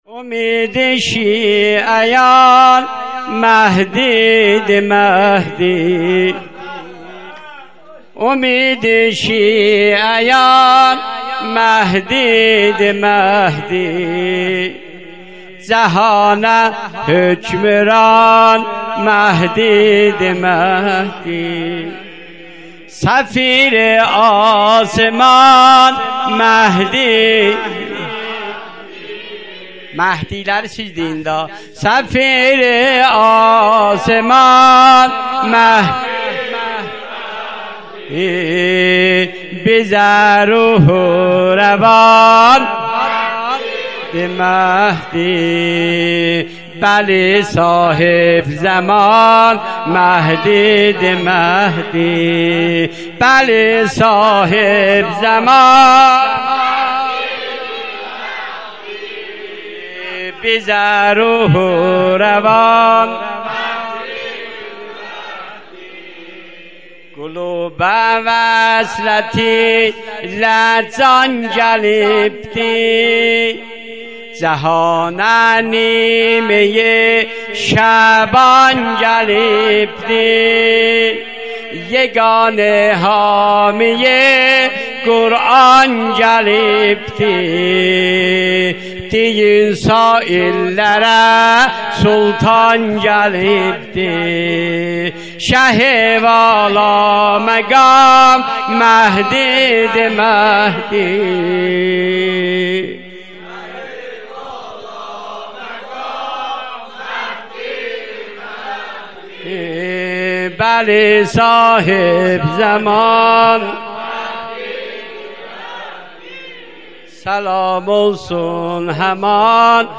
مدح
مراسم نیمه شعبان سال 97